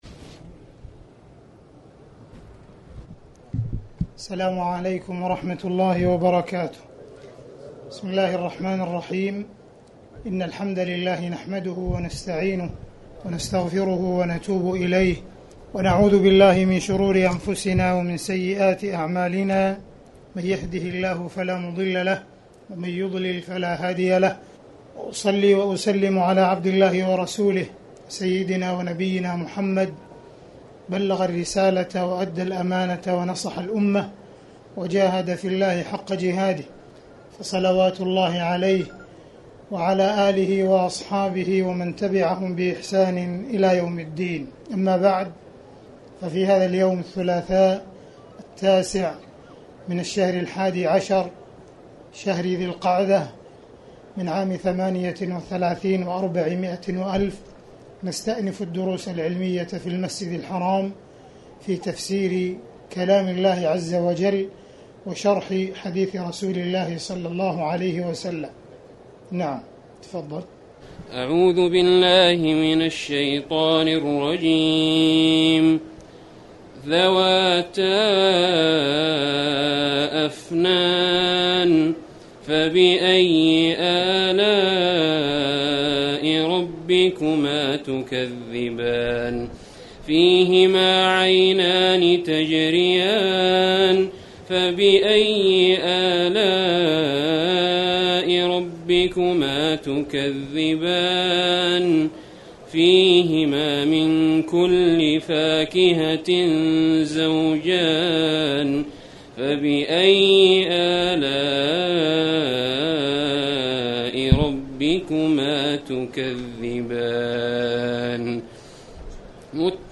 تاريخ النشر ٩ ذو القعدة ١٤٣٨ هـ المكان: المسجد الحرام الشيخ: معالي الشيخ أ.د. عبدالرحمن بن عبدالعزيز السديس معالي الشيخ أ.د. عبدالرحمن بن عبدالعزيز السديس سورة الرحمن أية48